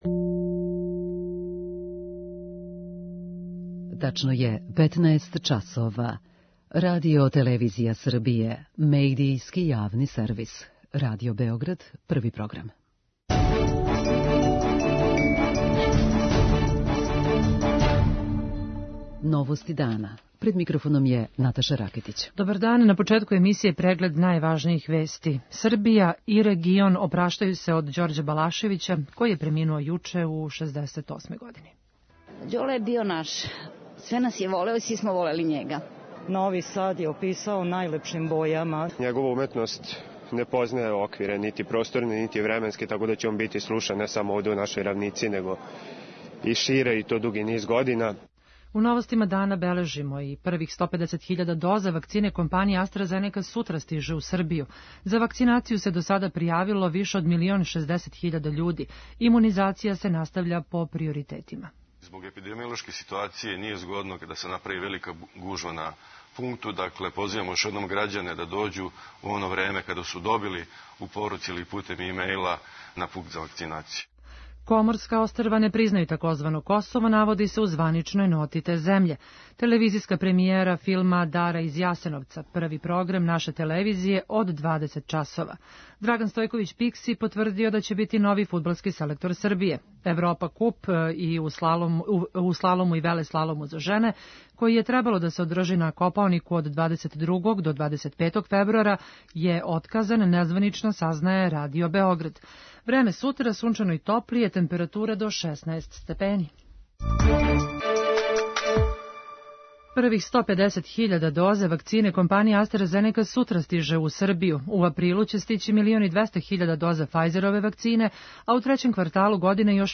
Na centralnom trgu - cveće i sveće uz zvuke hitova koji su proslavili njihov grad. преузми : 6.45 MB Новости дана Autor: Радио Београд 1 “Новости дана”, централна информативна емисија Првог програма Радио Београда емитује се од јесени 1958. године.